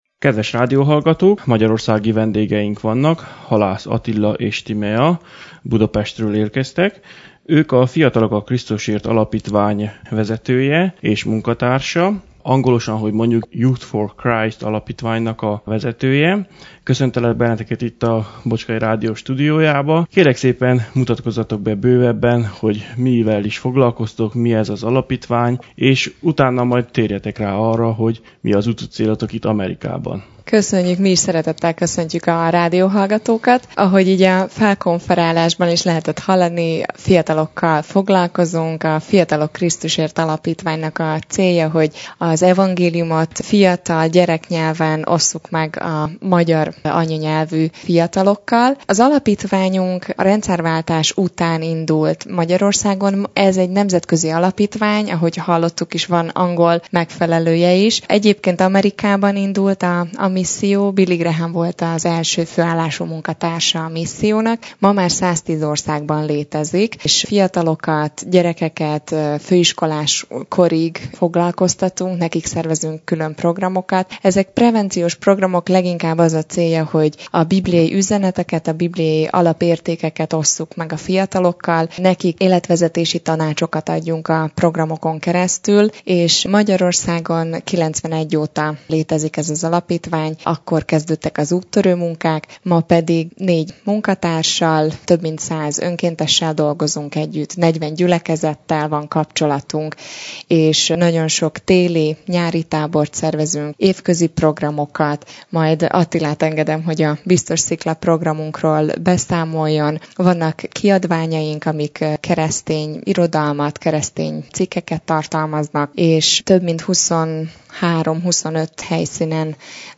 Kértem a két fiatalt, hogy számoljanak be az alapítvány tevékenységéről és amerikai útjuk céljáról.